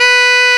Index of /90_sSampleCDs/Roland L-CD702/VOL-2/SAX_Tenor mf&ff/SAX_Tenor ff
SAX TENORF0P.wav